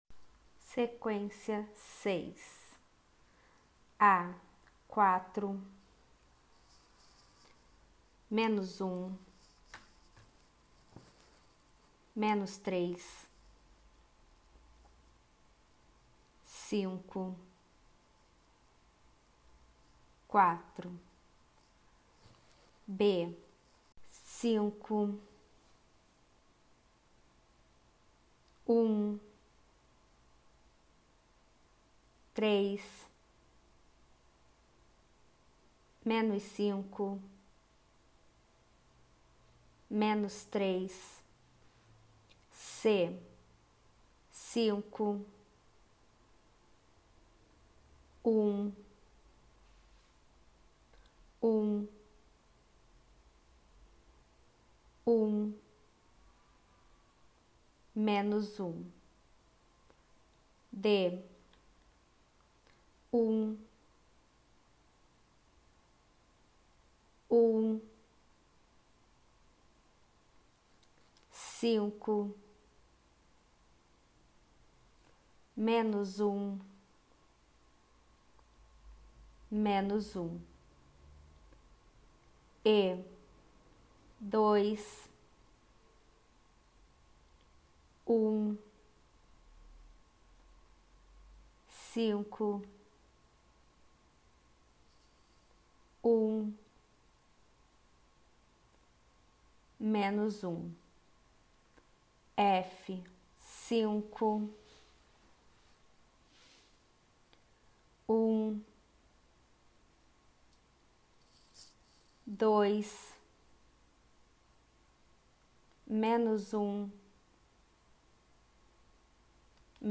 Ditados sem troca de base - Lento